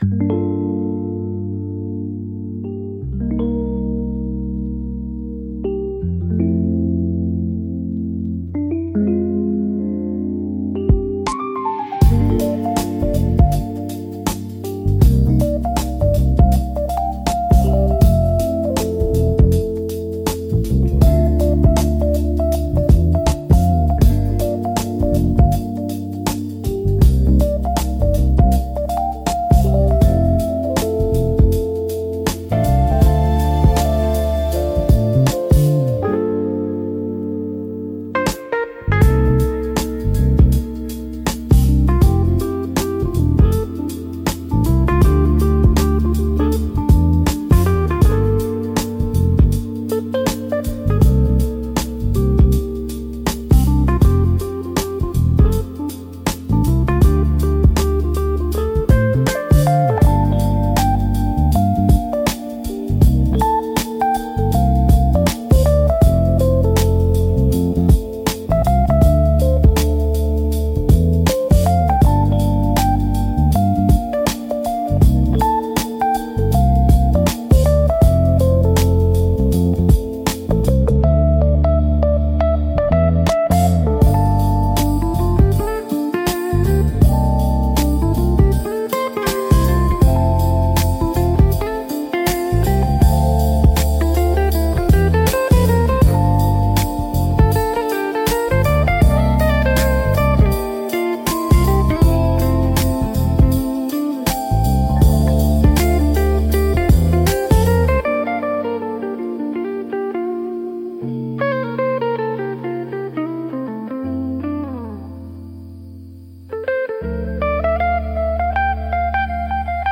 リラックス効果が高く、会話の邪魔をせず心地よい背景音として居心地の良さを高めます。